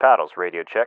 LSO-RadioCheck.ogg